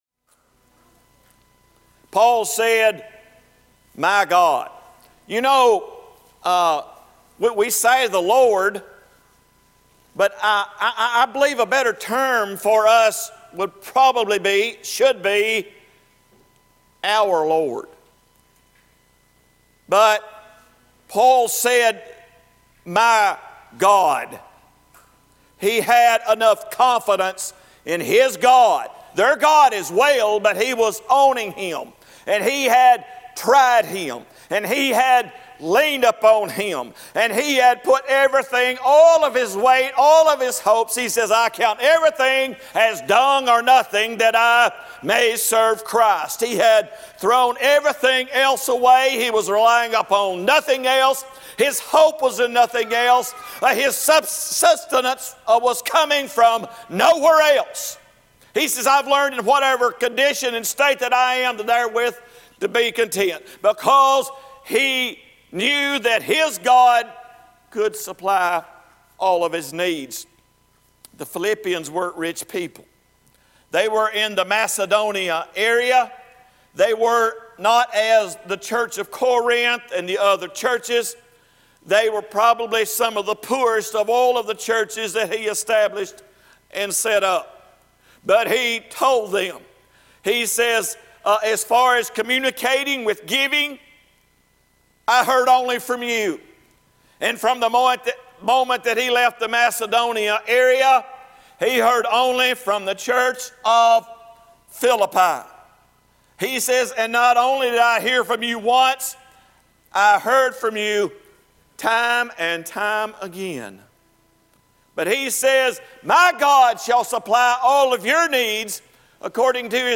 Sunday morning sermon from 2017-10-22 at Old Union Missionary Baptist Church in Bowling Green, Kentucky.
Sermons from our Sunday morning worship services.